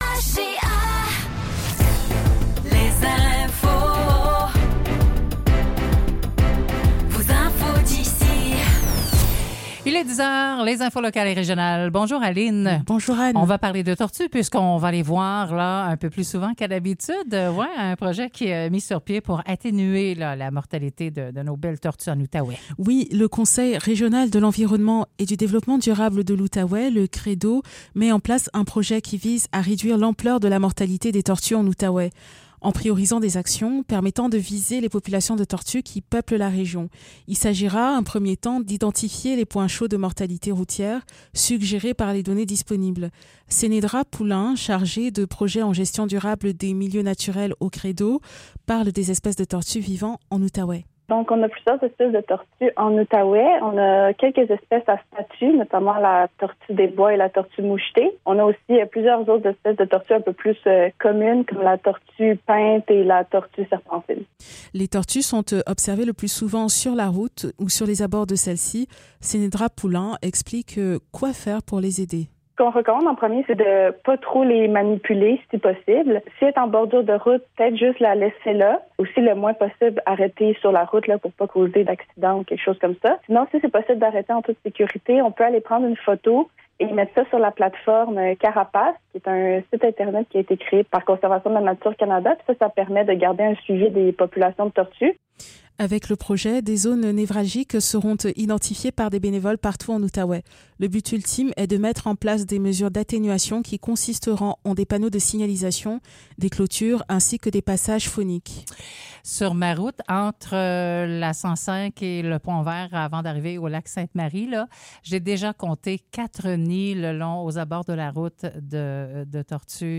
Nouvelles locales - 22 mars 2024 - 10 h